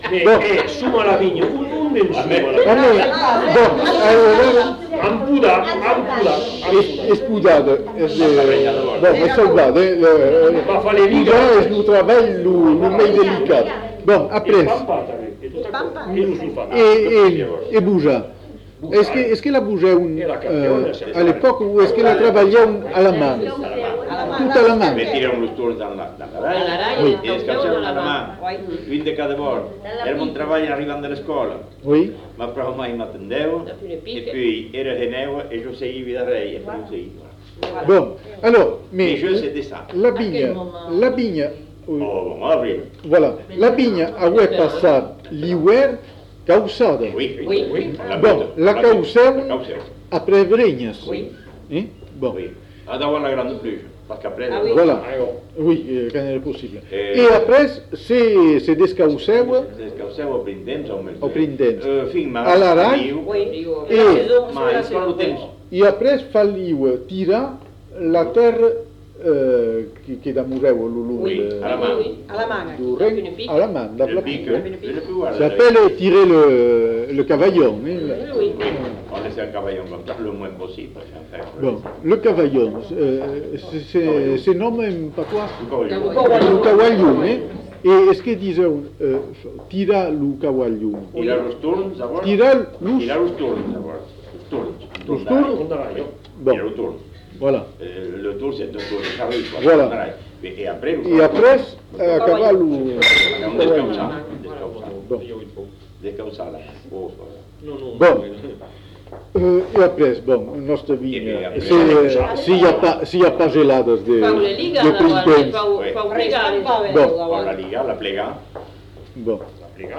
Lieu : Bazas
Genre : témoignage thématique
Plusieurs informateurs ne sont pas identifiés.